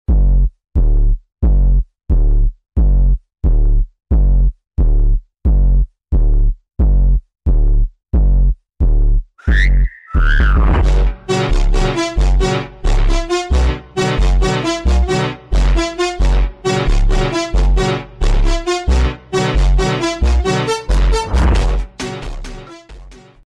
Ultra Slowed 23 seconds 131 Downloads Volkswagen Funk